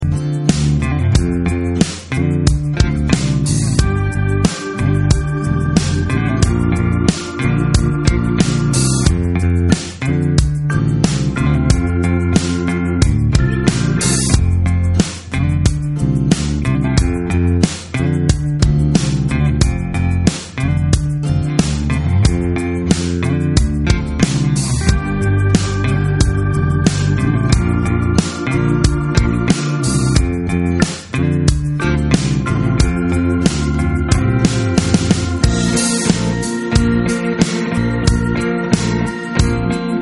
MP3 sample